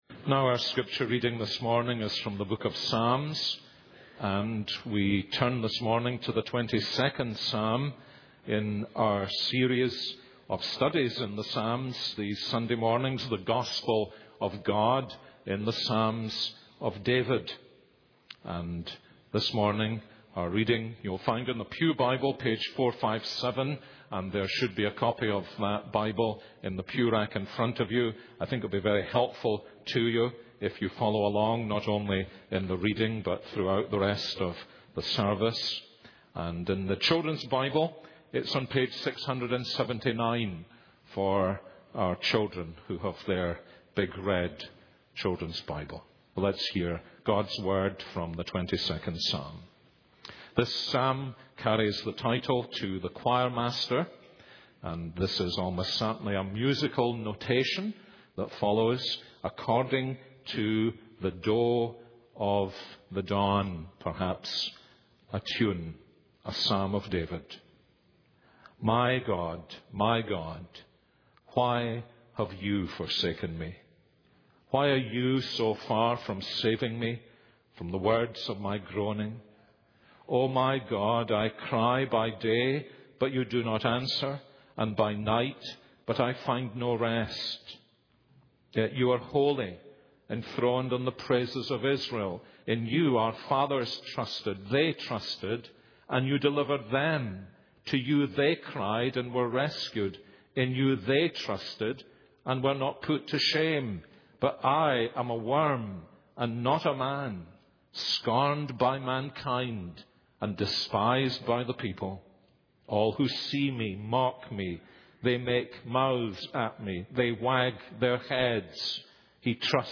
This is a sermon on Psalm 22:1-31.